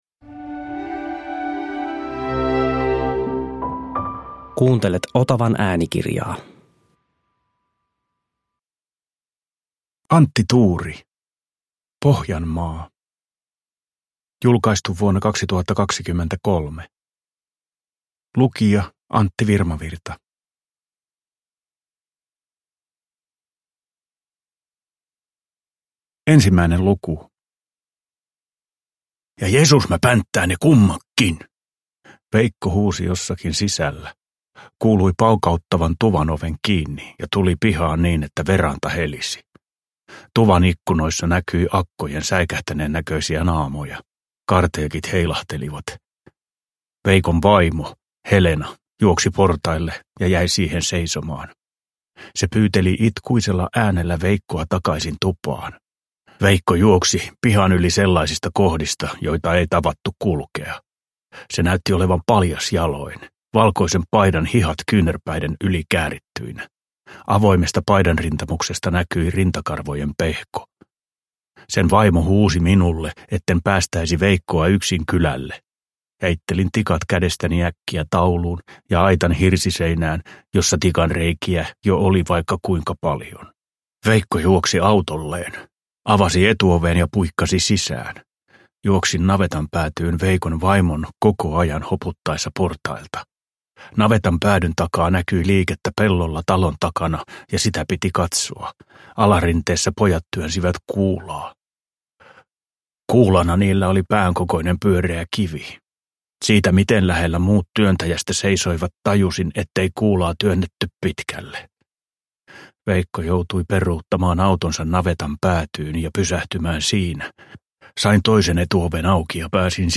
Pohjanmaa – Ljudbok – Laddas ner
Uppläsare: Antti Virmavirta